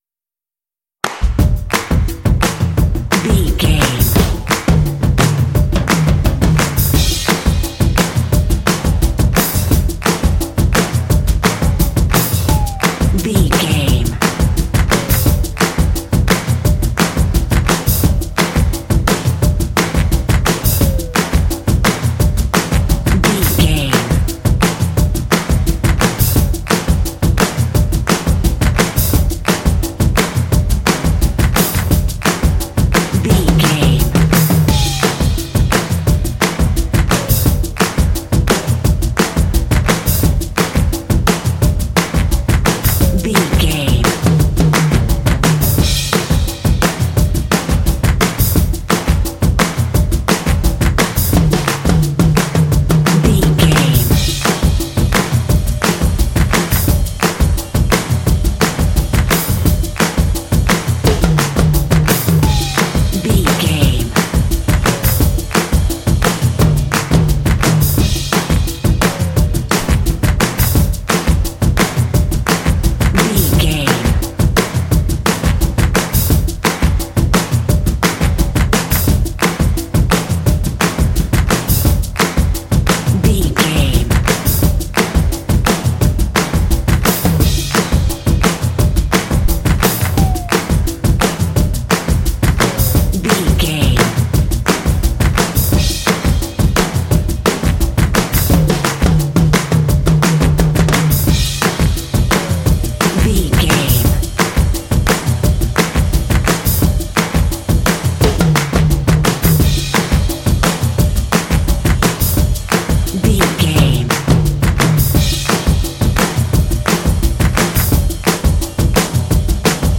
Ionian/Major
groovy
bouncy
cheerful/happy
percussion
blues
jazz